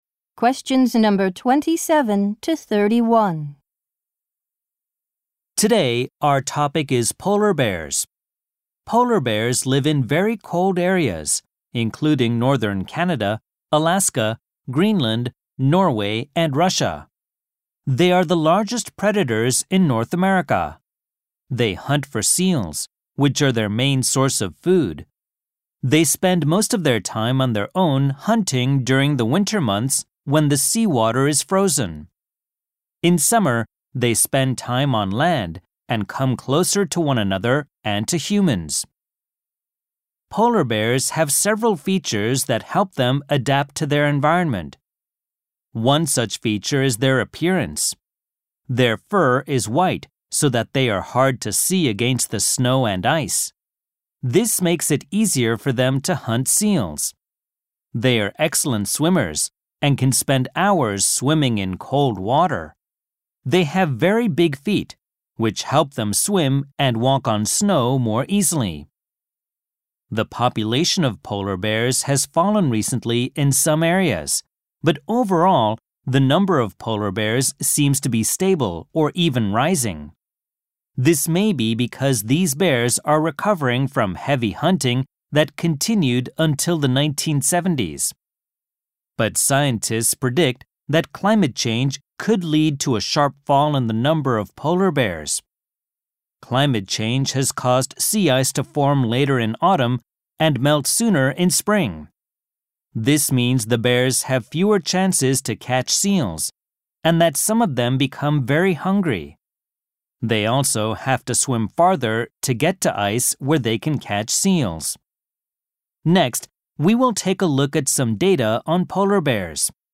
○共通テストの出題音声の大半を占める米英の話者の発話に慣れることを第一と考え，音声はアメリカ（北米）英語とイギリス英語で収録。
第5問　問27～31（アメリカ（北米）英語）